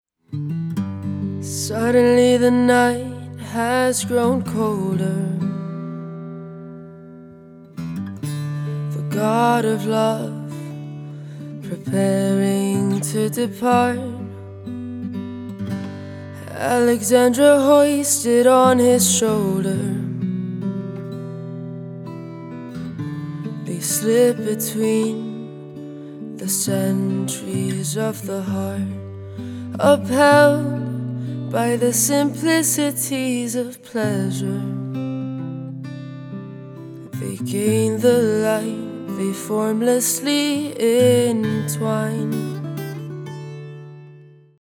a sultry and suspenseful score
guitarist